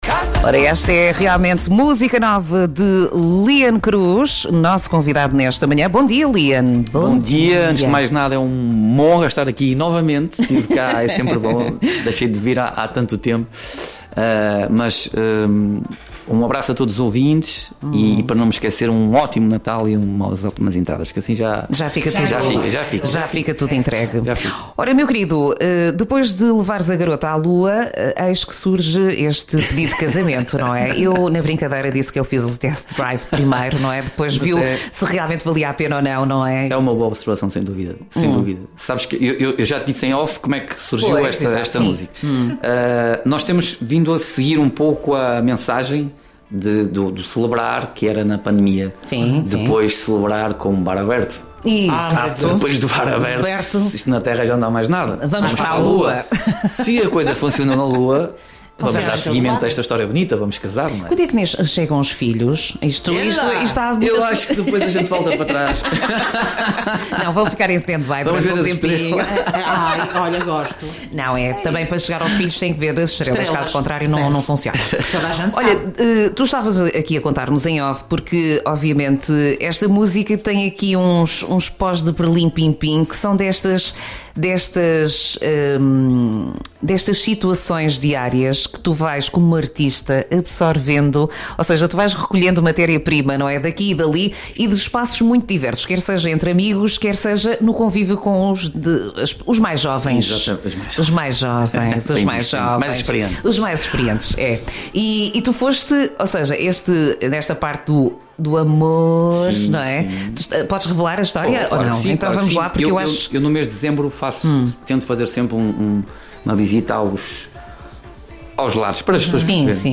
Entrevista em direto no programa “Manhãs NoAr” dia 19 de dezembro.